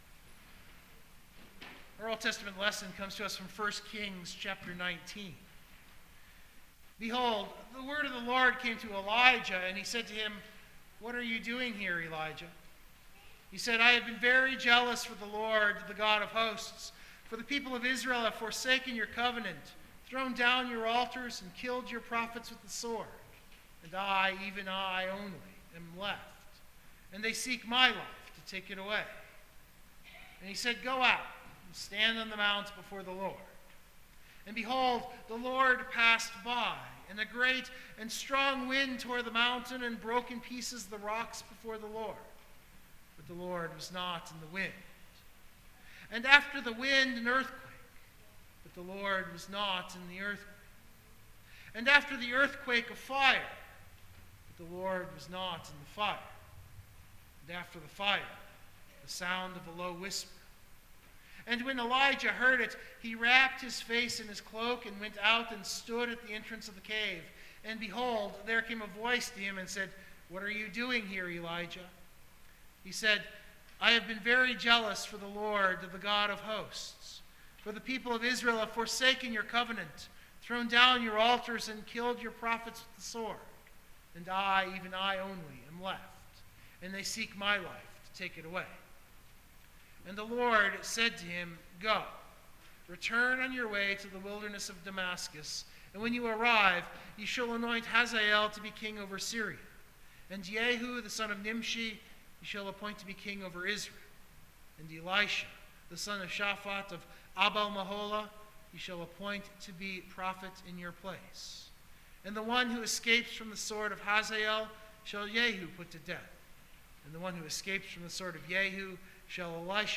Biblical Text: Luke 9:51-62 (Luke 9) Full Sermon Draft
Worship note: I’ve left in the recording Lutheran Service Book 856 , O Christ Who Called the Twelve.